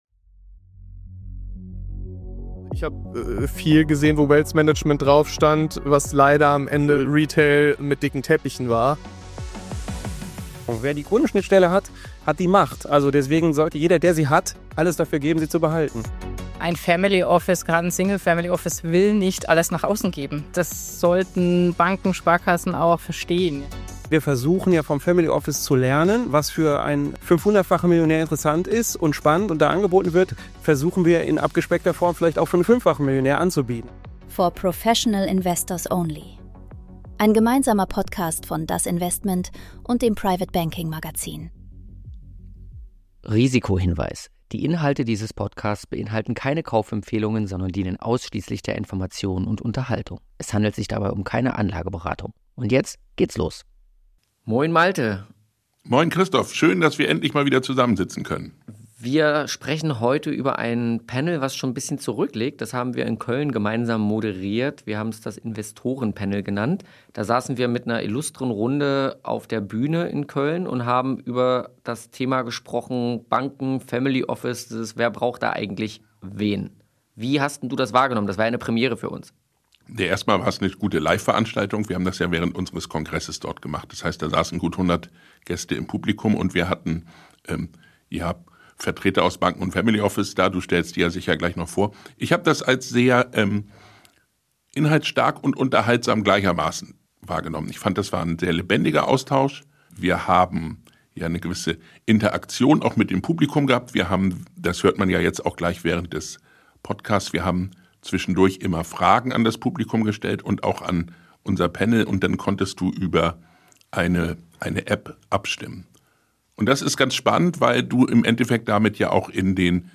Aber wie viel Vertrauen ist wirklich möglich, wenn einer strukturell immer auch Verkäufer bleibt? Aufgezeichnet live beim private banking kongress in Köln, mit Publikumsinteraktion und echten Meinungsunterschieden geben vier Praktiker ungewohnt offene Antworten. Es geht um Interessenkonflikte, KYC-Frust, Datenhoheit und die Frage, was „Wealth Management" wirklich bedeutet – wenn nicht Retail mit dicken Teppichen.